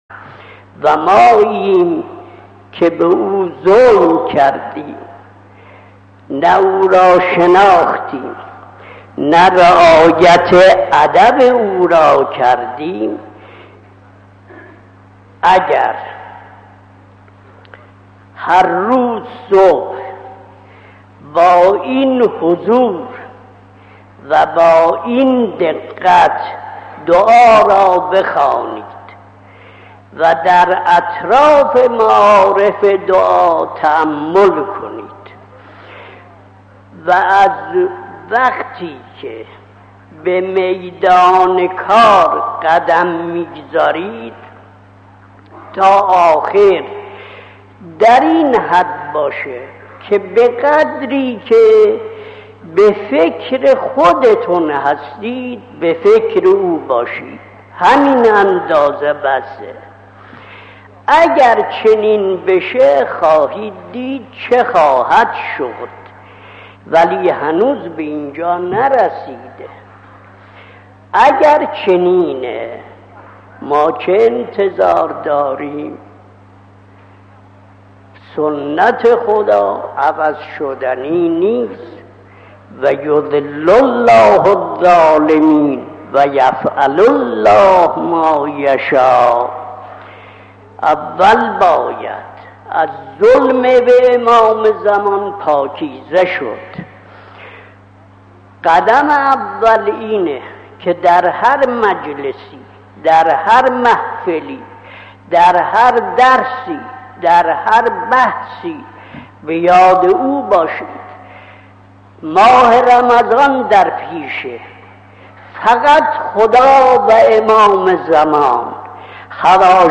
به گزارش خبرگزاری حوزه، حضرت آیت الله وحید خراسانی در یکی از سخنرانی های خود به غفلت از امام زمان علیه السلام و ریشه همه بدبختی های فردی و اجتماعی بشریت اشاره کرده است که تقدیم شما فرهیختگان می شود.